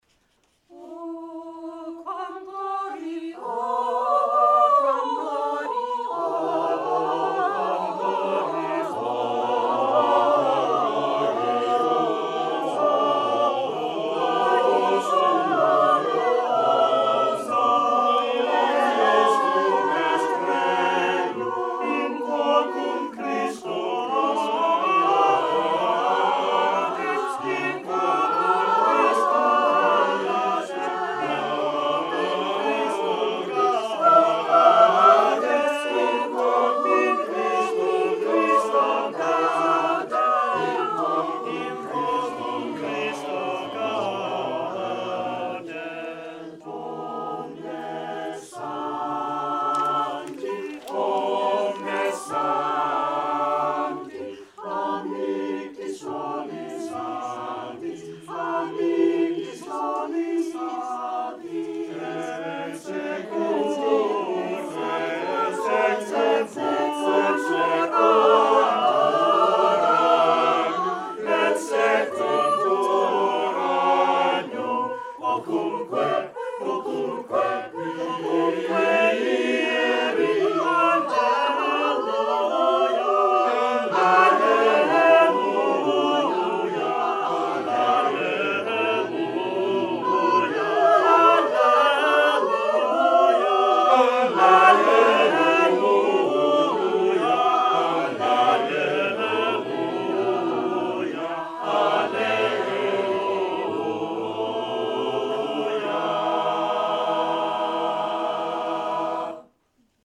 The Renaissance Street Singers' 45th-Anniversary Loft Concert, 2018